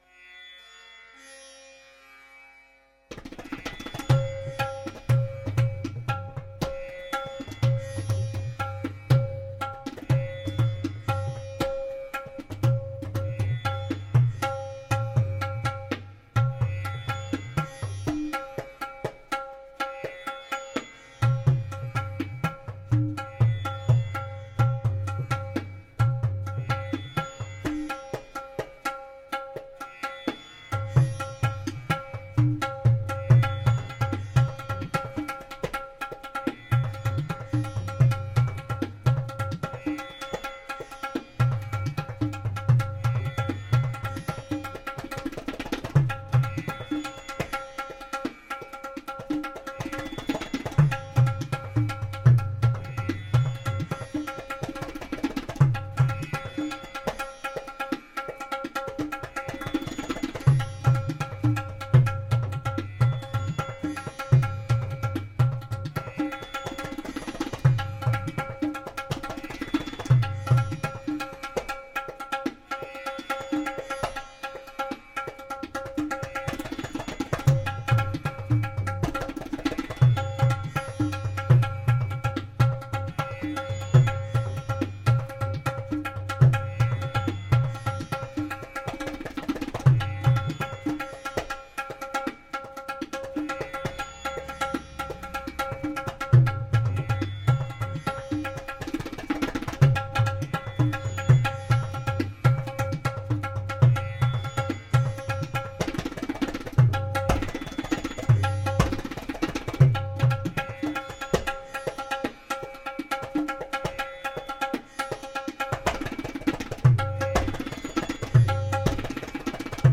World, Indian, Instrumental World, Indian Influenced
Sitar